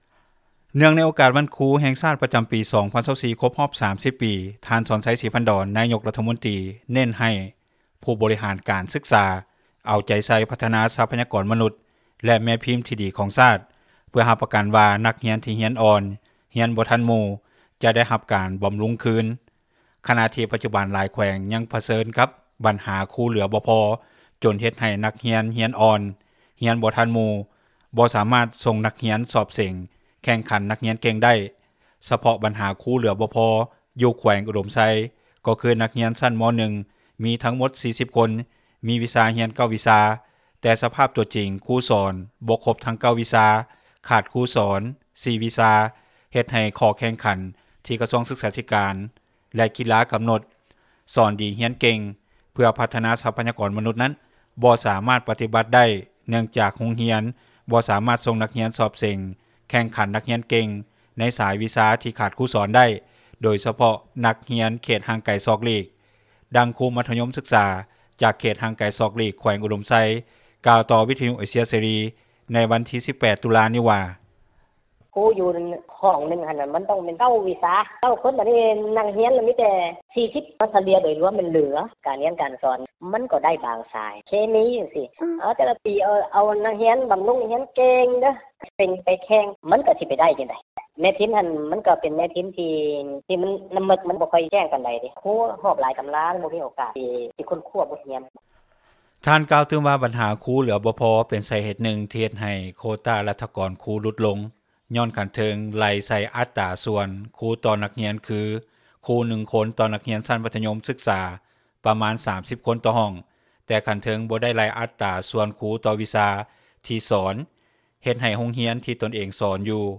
ເຮັດໃຫ້ຂໍ້ແຂ່ງຂັນທີ່ກະຊວງສຶກສາທິການແລະກິລາກຳນົດ “ສອນດີຮຽນເກັ່ງ” ເພື່ອພັດທະນາຊັບພະຍາກອນມະນຸດນັ້ນບໍ່ສາມາດປະຕິບັດໄດ້ເນື່ອງຈາກໂຮງຮຽນບໍ່ສາມາດສົ່ງນັກຮຽນສອບເສັງແຂ່ງຂັນນັກຮຽນເກັ່ງໃນສາຍວິຊາທີ່ຂາດຄູສອນໄດ້, ໂດຍສະເພາະນັກຮຽນເຂດຫ່າງໄກສອກຫຼີກ. ດັ່ງຄູມັດທະຍົມສຶກສາຈາກເຂດຫ່າງໄກສອກຫຼີກແຂວງອຸດົມໄຊກ່າວຕໍ່ວິິທຍຸເອເຊັຽເສຣີໃນວັນທີ 18 ຕຸລານີ້ວ່າ:
ດັ່ງຄູຊັ້ນປະຖົມເຂດຫ່າງໄກສອກຫຼີກແຂວງຊຽງຂວາງກ່າວວ່າ.
ດັ່ງເຈົ້າໜ້າທີ່ແຂວງຜົ້ງສາລີກ່າວວ່າ.
ດັ່ງເຈົ້າໜ້າທີ່ແຂວງສະຫວັນນະເຂດກ່າວວ່າ.